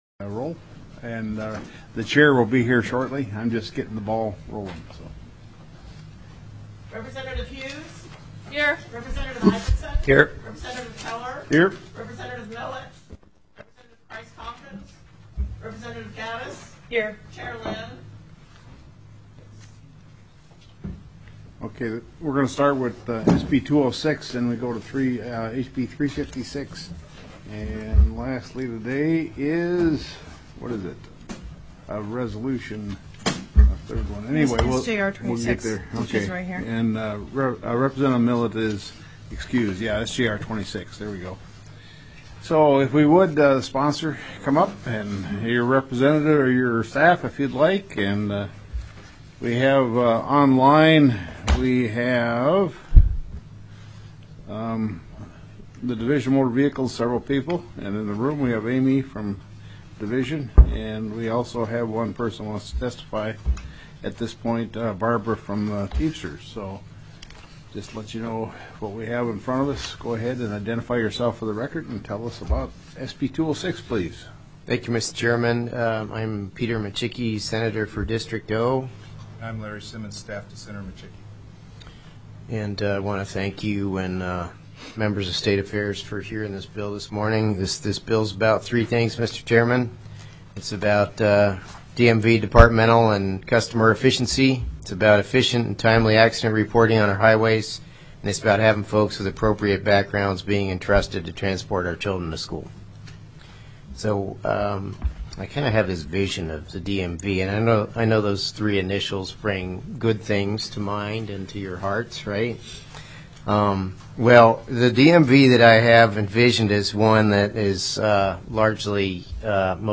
TELECONFERENCED
8:36:06 AM REPRESENTATIVE PAUL SEATON, Alaska State Legislature, as prime sponsor, presented HB 356 .